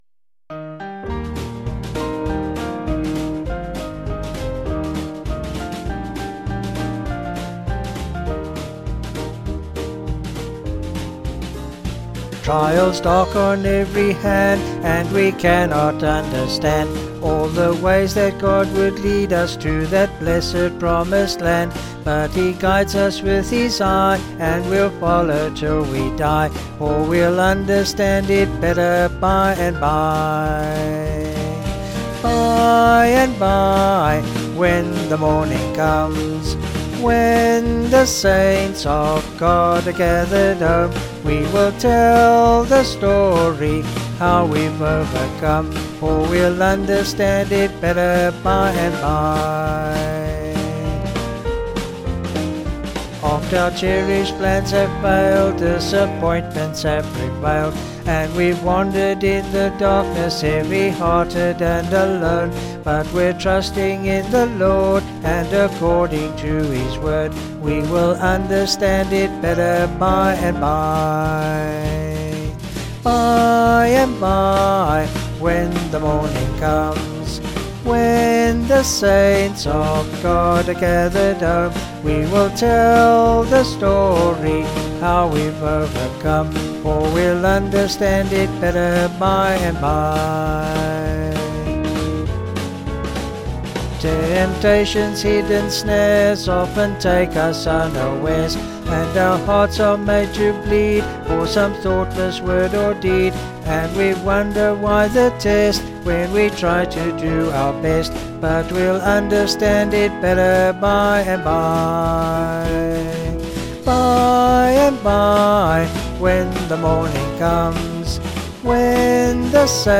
Vocals and Band   264.5kb